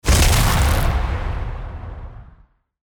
attack_skill_set.mp3